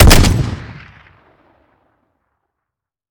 weap_hdromeo_fire_plr_01.ogg